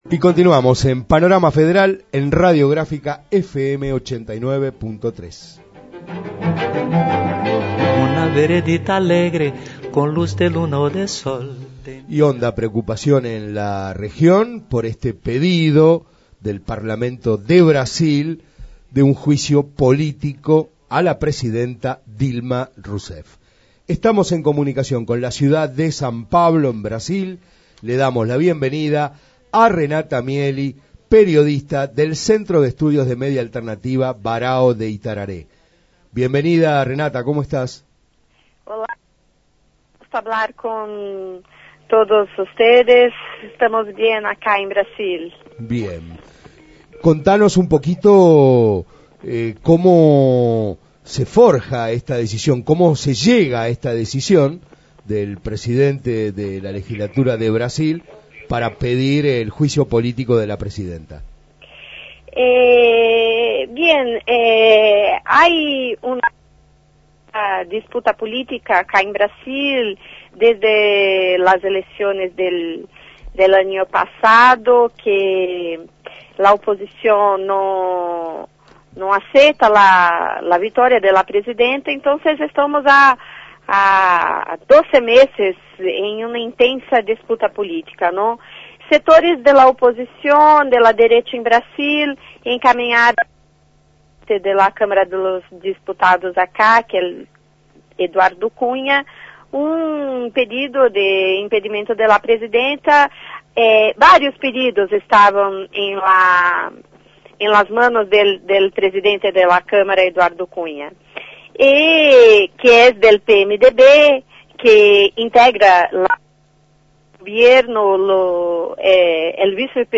En diálogo telefónico